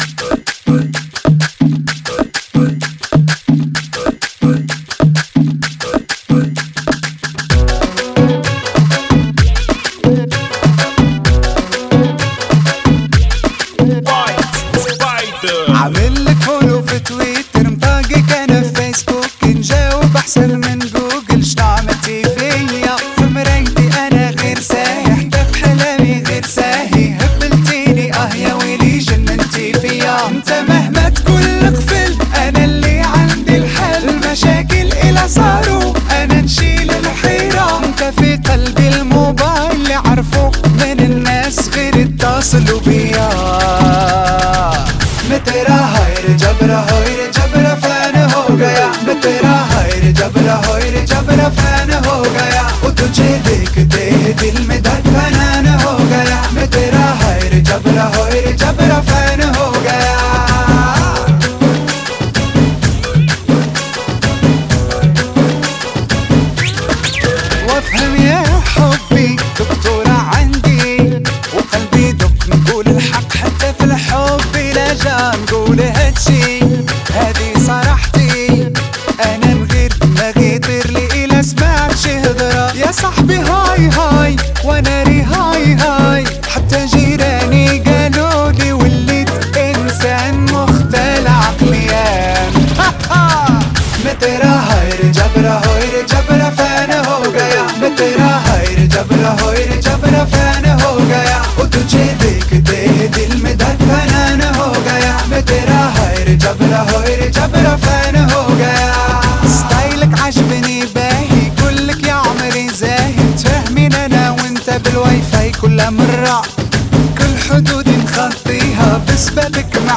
Funky [ 128 Bpm ]